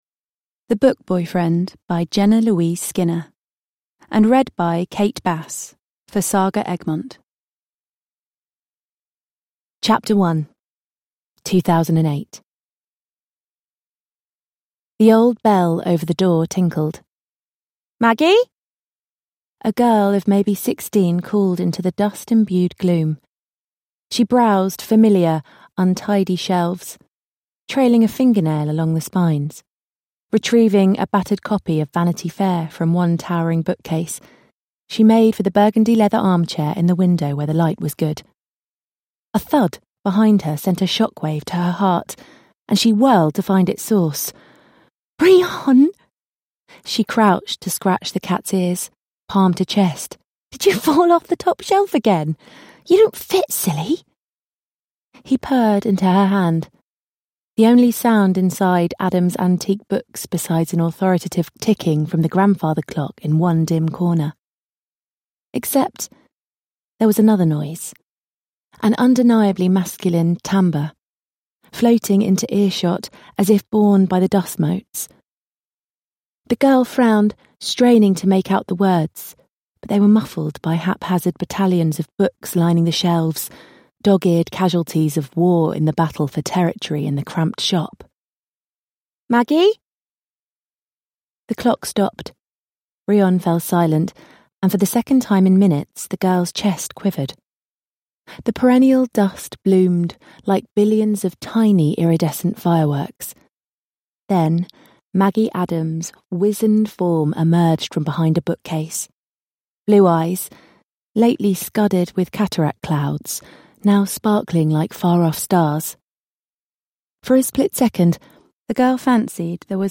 The Book Boyfriend: A Plus–Size Paranormal Romance – Ljudbok